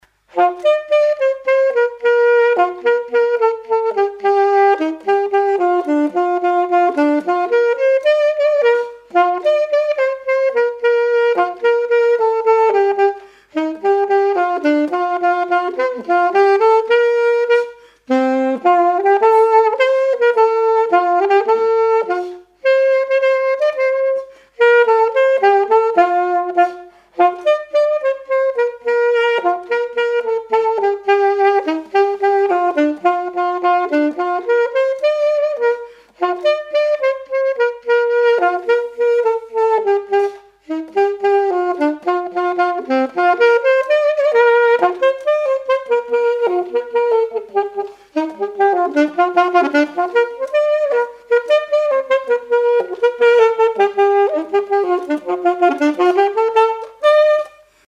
Quadrille de Chaillé
Vendée
danse : quadrille : galop
Pièce musicale inédite